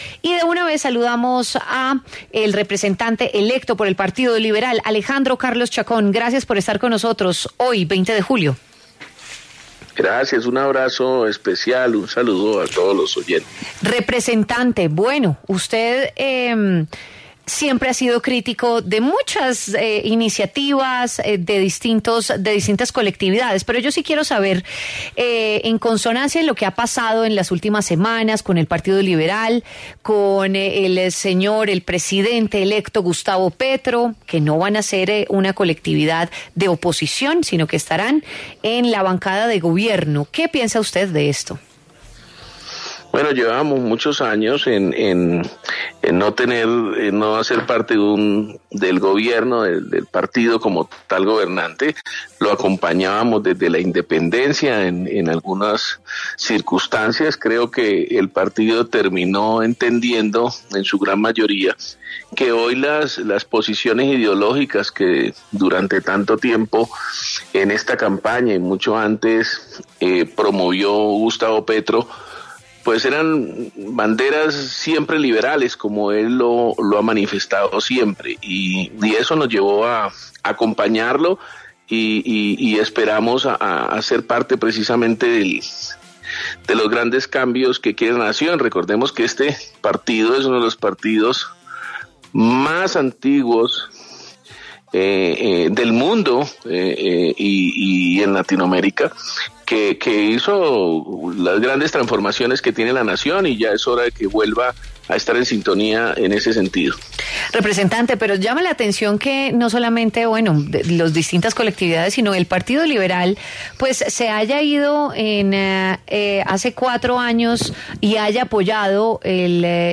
Alejandro Carlos Chacón, representante electo por el Partido Liberal, se pronunció en La W sobre la decisión de su colectividad de ser partido de gobierno de Gustavo Petro.